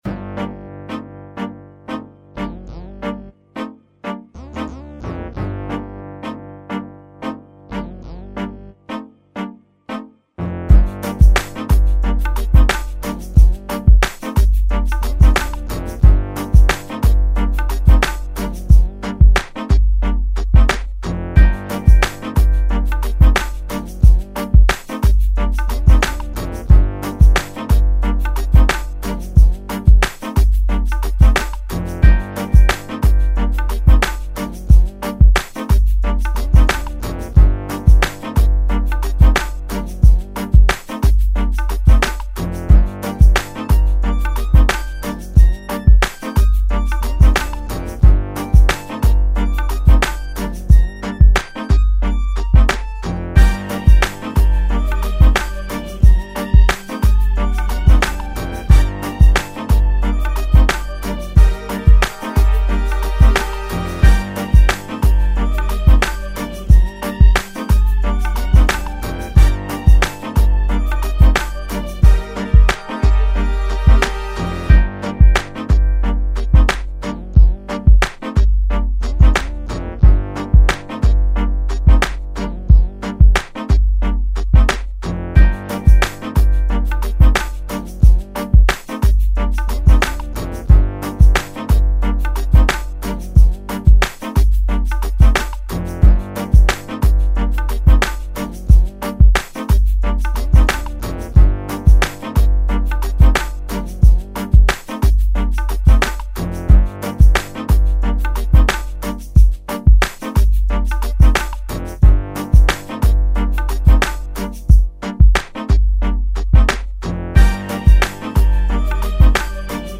Club
West Coast